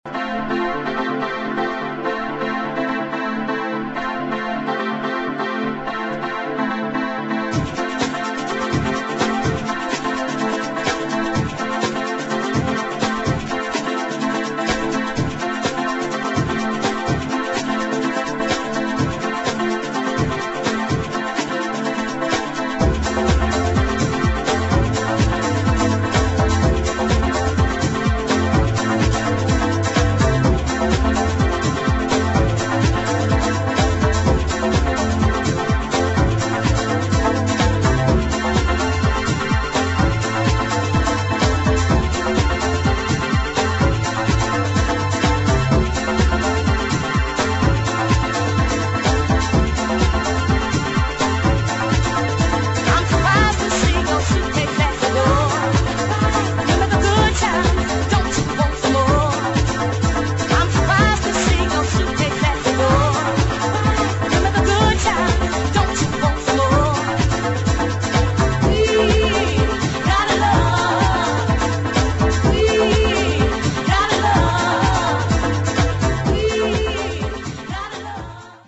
[ HOUSE / GARAGE HOUSE ]